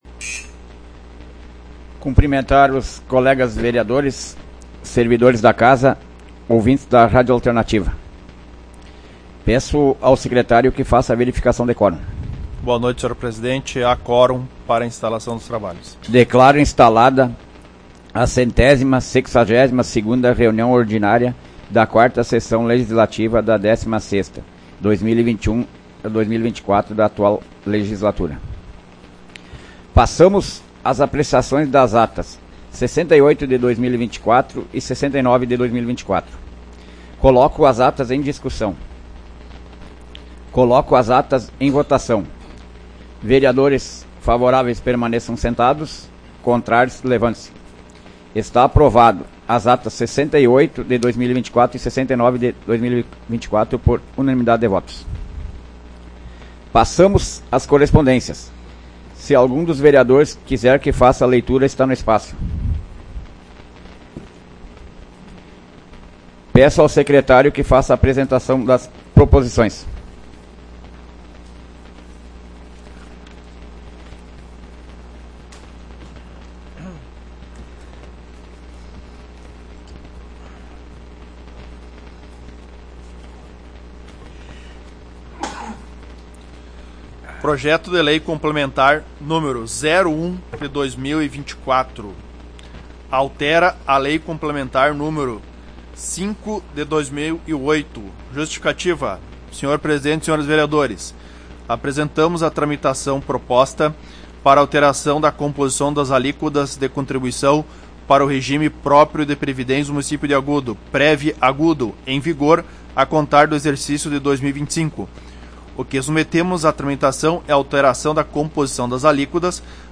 Áudio da 162ª Sessão Plenária Ordinária da 16ª Legislatura, de 16 de dezembro de 2024